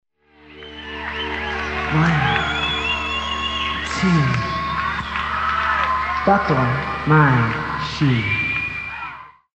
geddy-buckles-his-shoe.mp3